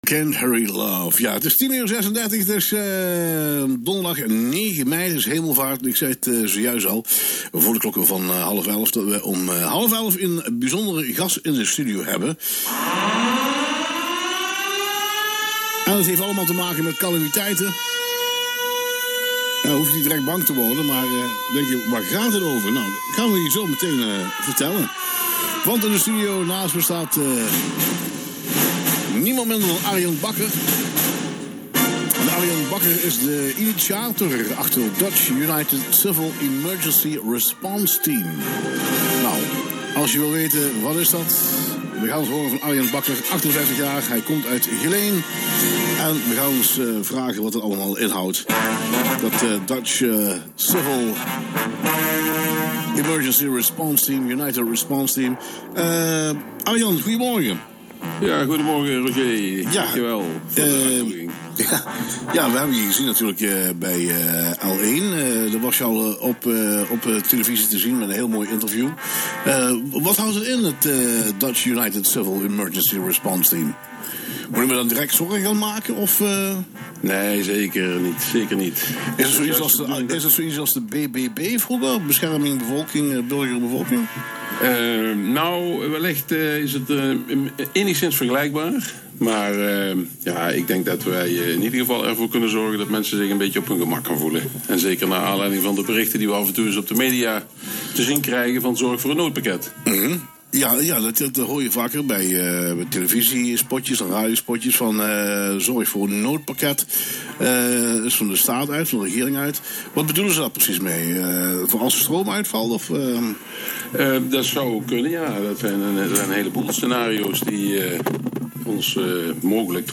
Interview Falcon FM Radio: FalconRadioUCERinterview.mp3